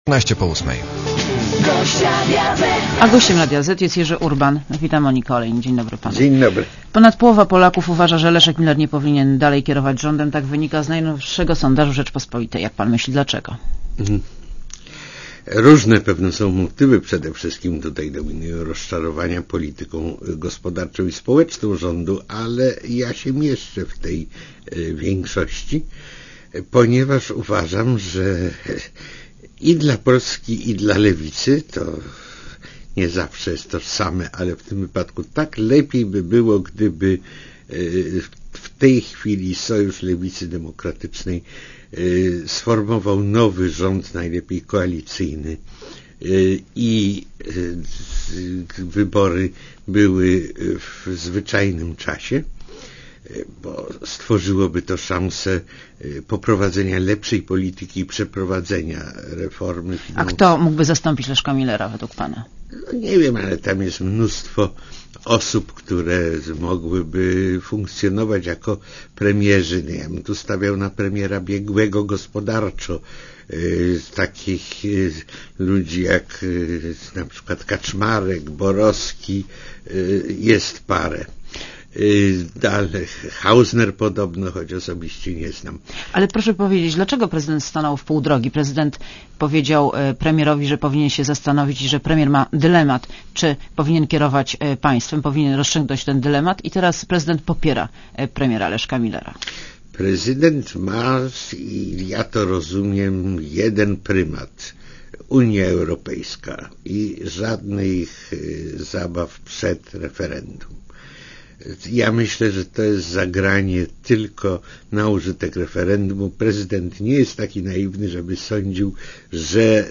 Monika Olejnik rozmawia z Jerzym Urbanem - redaktorem naczelnym tygodnika "Nie"